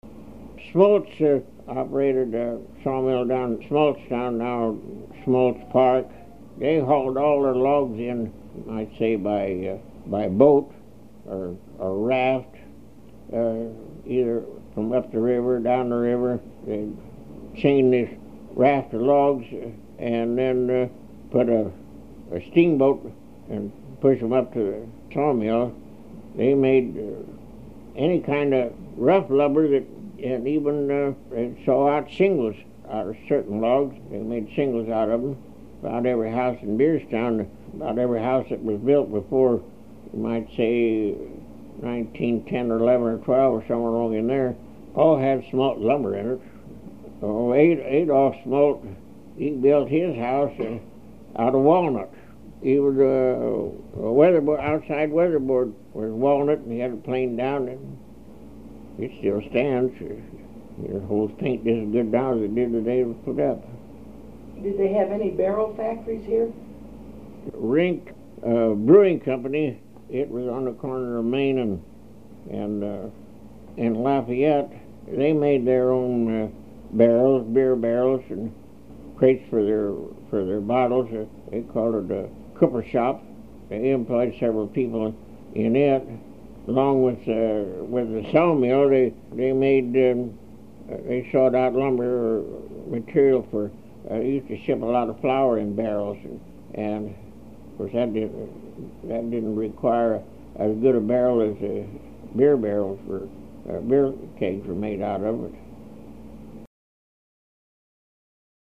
HTR Oral History, 07/23/1